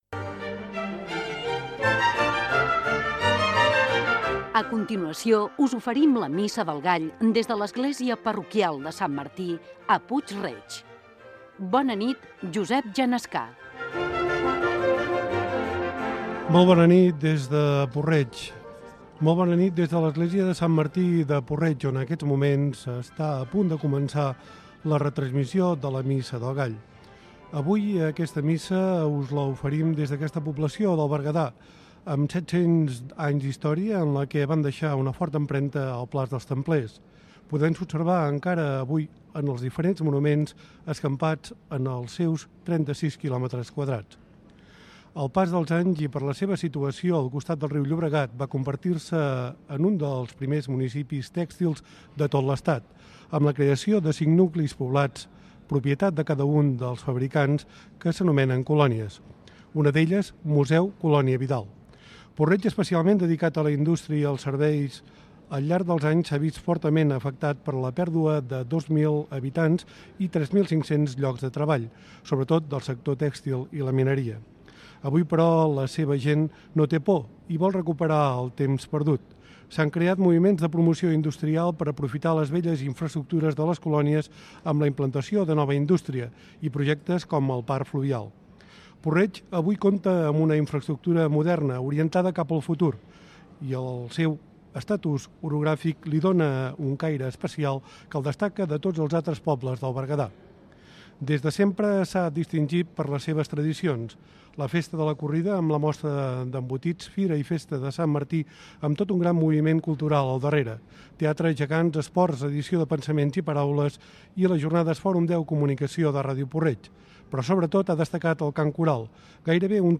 Careta i inici de la transmissió de la missa del gall des de l'Església parroquial de Sant Martí, a Puig-reig. Dades de la localitat, transformació dels últims anys i inici de la cerimònia religiosa. Canta el Cor Parroquial de Puig-reig.